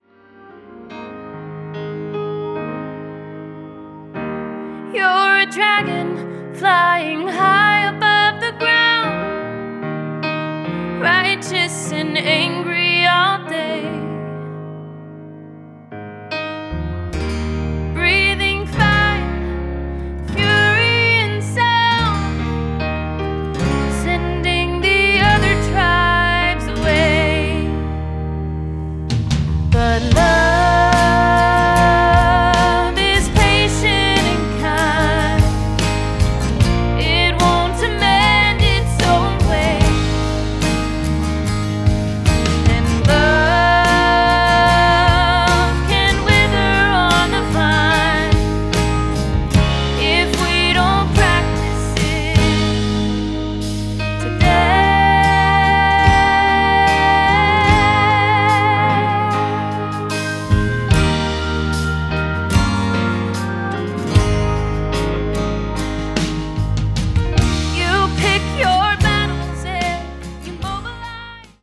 backed once again by the top Nashville studio musicians.